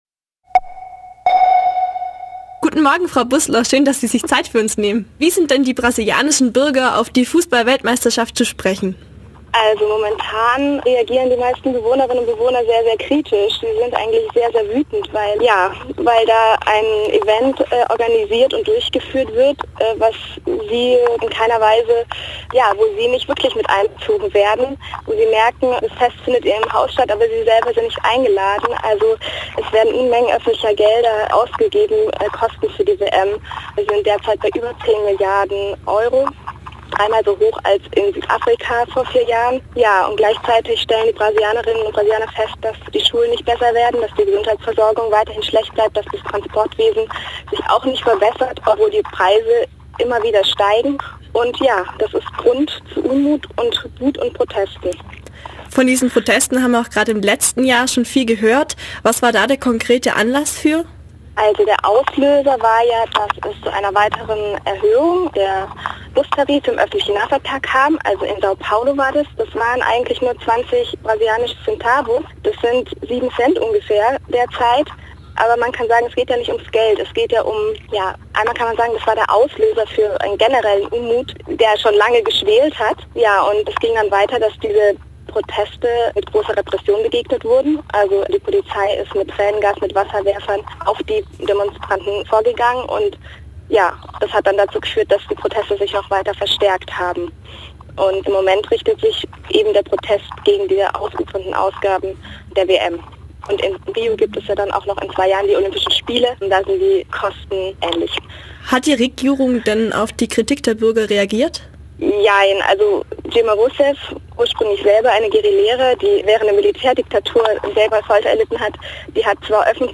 interview_wm.mp3